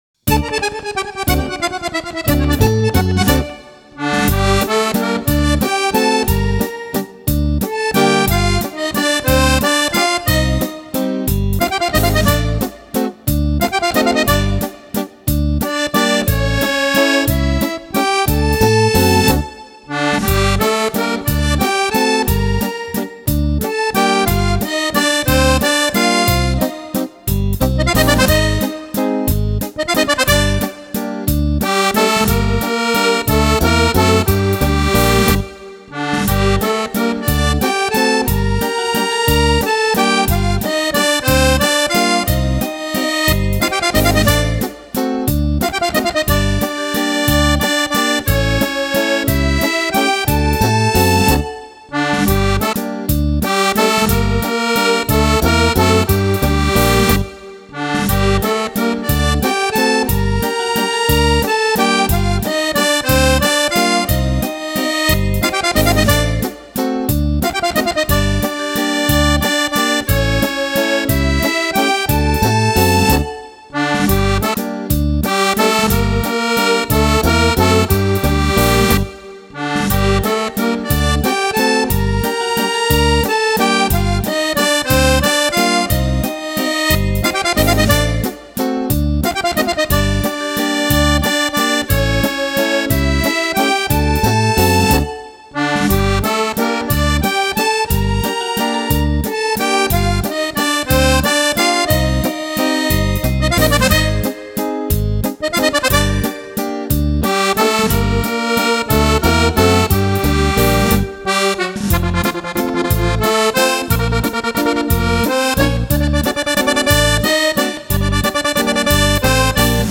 Valzer
ballabili per sax e orchestra stile Romagnolo.